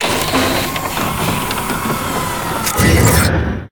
repair.ogg